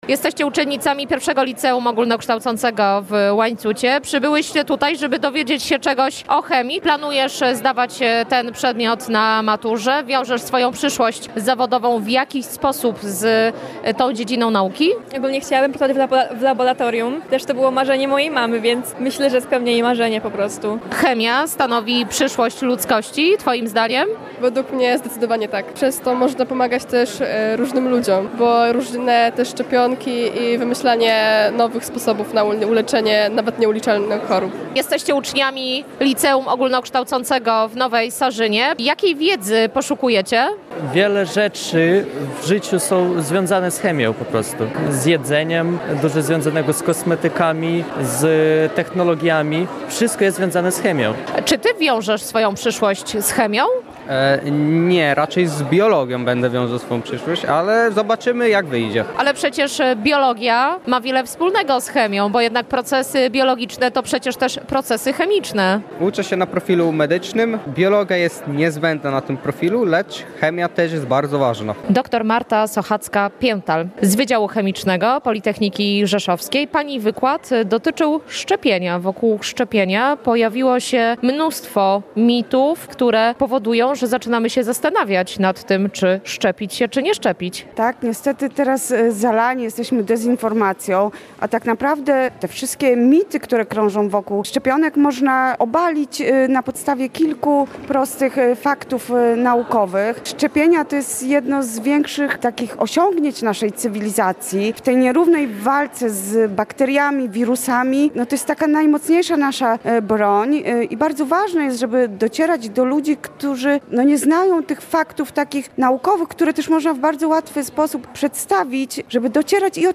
Wykłady, pokazy chemiczne, prezentacja kół naukowych i zwiedzanie Wydziału Chemicznego Politechniki Rzeszowskiej przygotowano dla uczniów podkarpackich szkół średnich. W środę kilkuset uczniów wraz z nauczycielami wzięło udział w trzydziestym seminarium pod hasłem „Wybrane problemy chemii”.